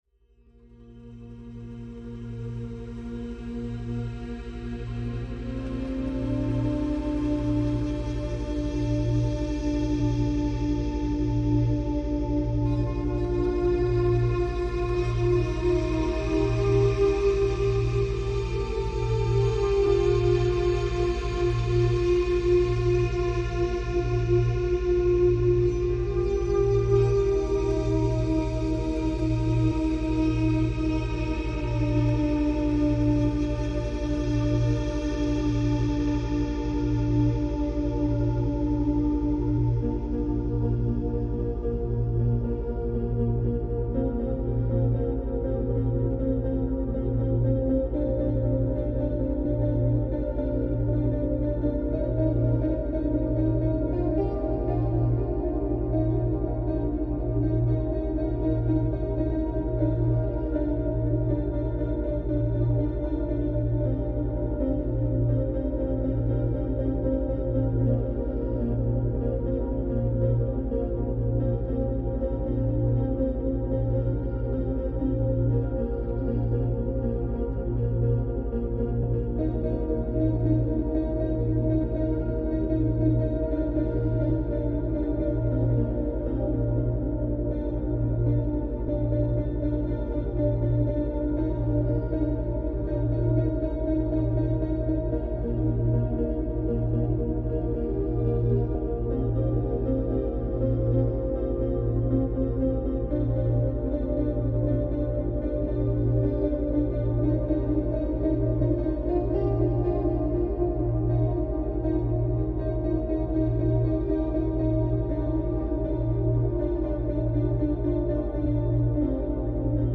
Техника: Целенаправленная стимуляция когнитивных процессов через синхронизацию мозговых волн в бета-диапазоне (15-21 Гц). Метод использует чистые бинауральные ритмы для индукции состояния активного бодрствования, оптимального для целенаправленной умственной деятельности.
Защита вашей ауры Раздел: Бета-волны Размер: 55 MB Длительность: 59 минут Качество: 320 kbps Релиз: 30 ноября 2025 Слушать Скачать Бета-волны воспроизводятся на разных частотах в каждом ухе, поэтому обязательно слушайте в наушниках.
Базовый слой: Чистые синусоидальные волны с минимальными гармоническими искажениями Ритмическая основа: Стабильные бета-биения с равномерной амплитудой Акустическое оформление: Нейтральный звуковой фон без отвлекающих элементов Динамика: Постоянный уровень звукового давления для поддержания концентрации